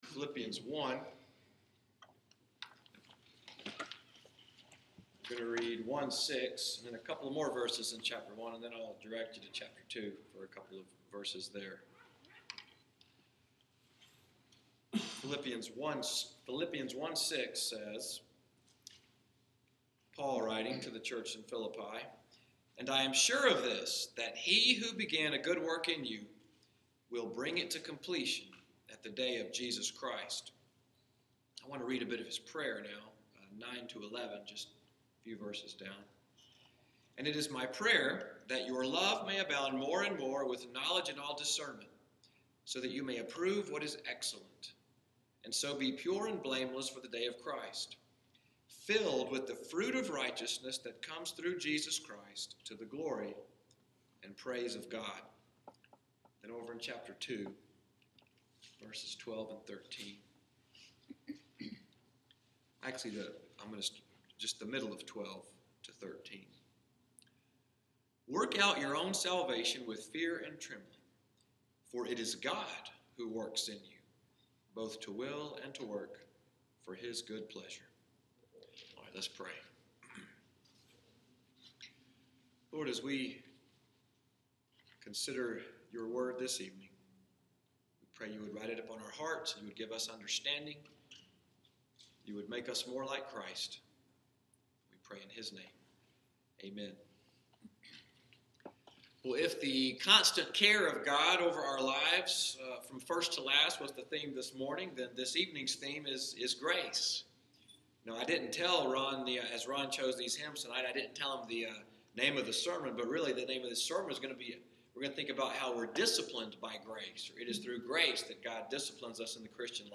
EVENING WORSHIP Discipleship / Teaching sermon at New Covenant: Disciplined by Grace